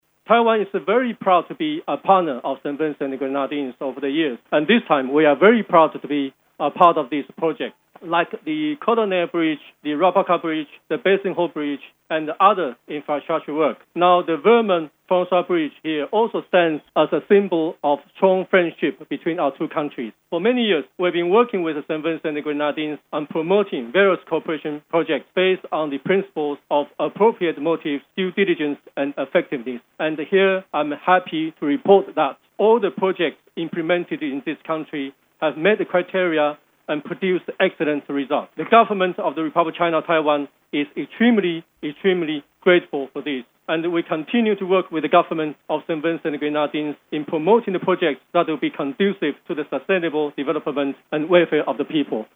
Meanwhile … Taiwanese Ambassador to St. Vincent and the Grenadines, Baushan Ger congratulated the government on the successful completion of the bridge, which he said symbolizes the strong friendship between the two countries.